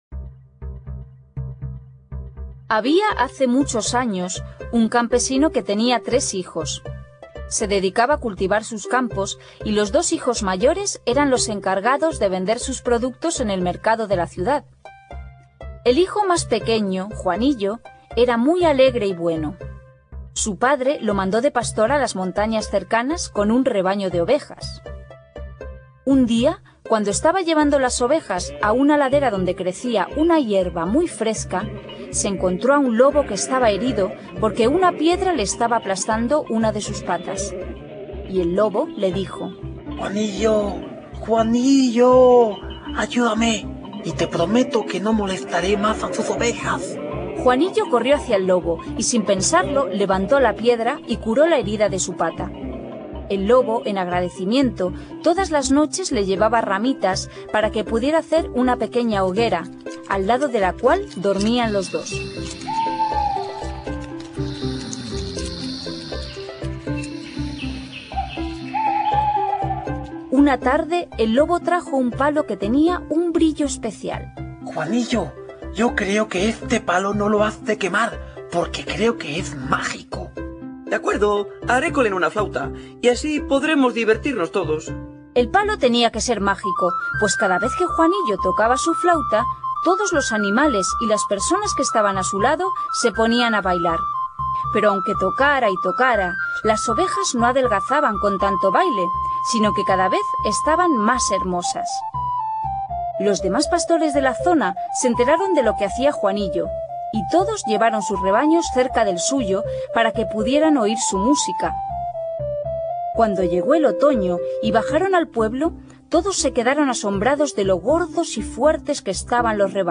cuento
Sonidos: Cuentos infantiles